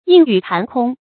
硬语盘空 yìng yǔ pán kōng 成语解释 硬：遒劲有力的语句；盘：盘旋。